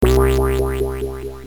图秀网震荡频道，提供震荡音频素材。